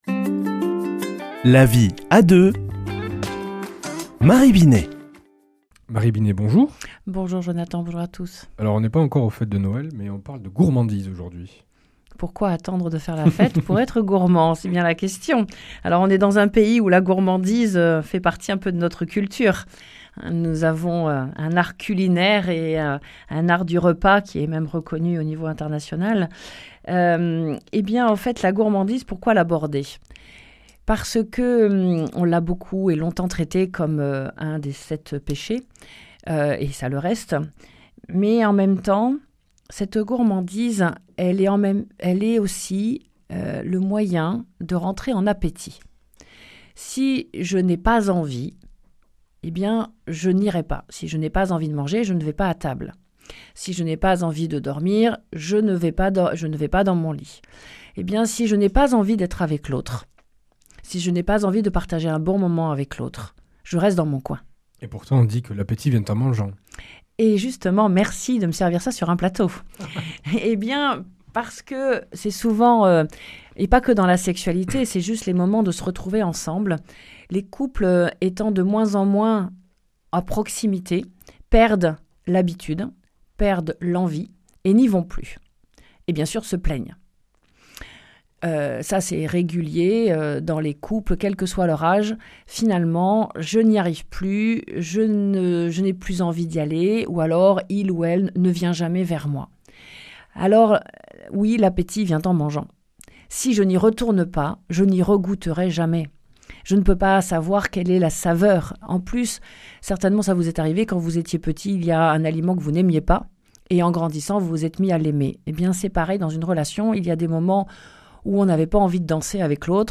mardi 23 septembre 2025 Chronique La vie à deux Durée 4 min